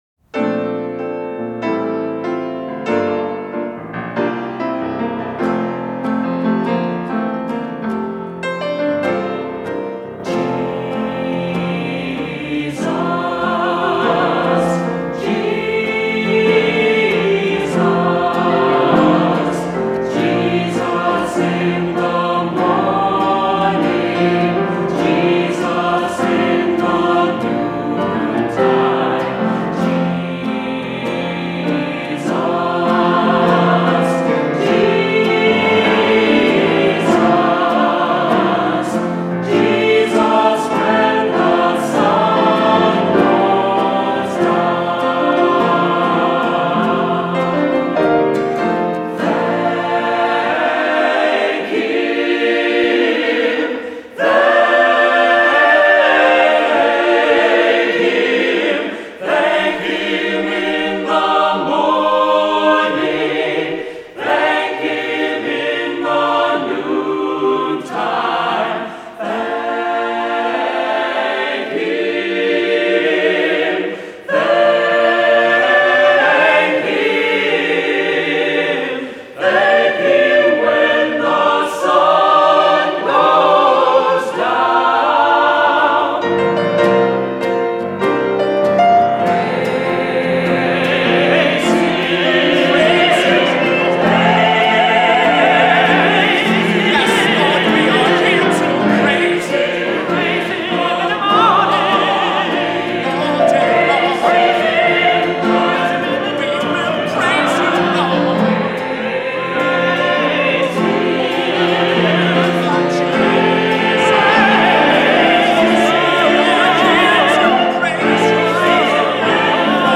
Voicing: Unison, optional SATB Choir, Assembly